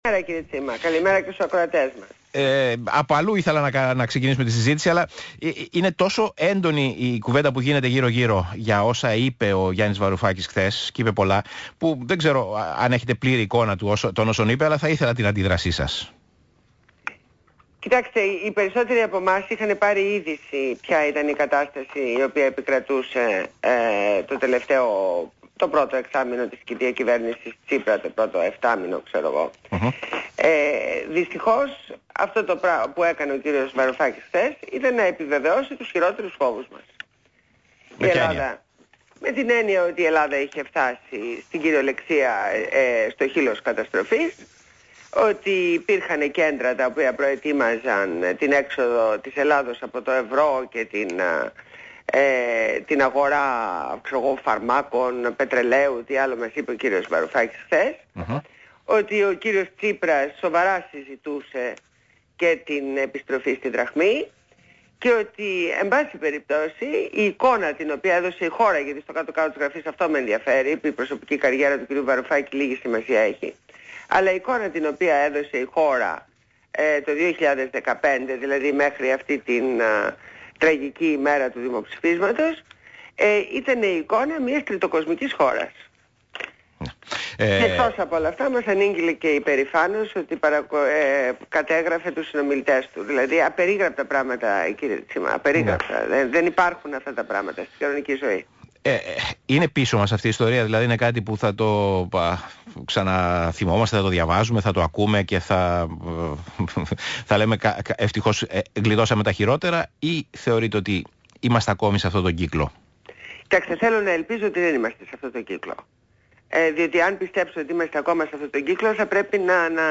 Συνέντευξη στο ραδιόφωνο του ΣΚΑΙ στο δημοσιογράφο Π. Τσίμα.